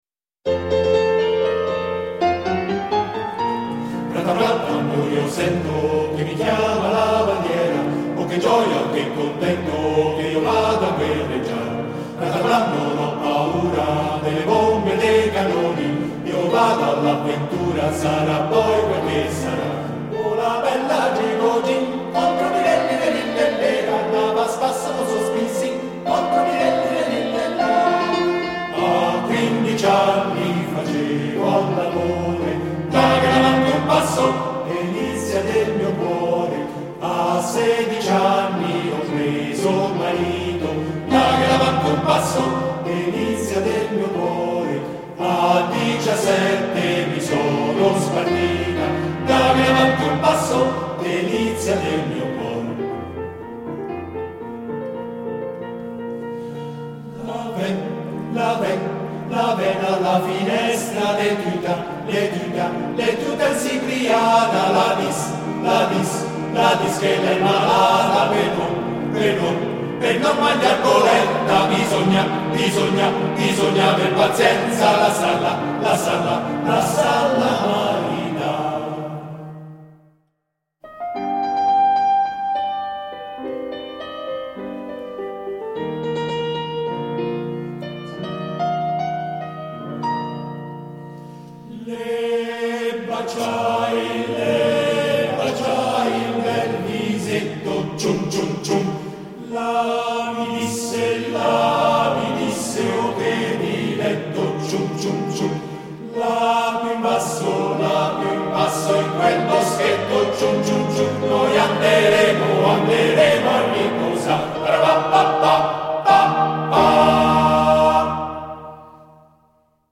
Esecutore: Coro CAI La Martinella Firenze